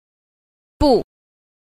a. 不 – bù – bất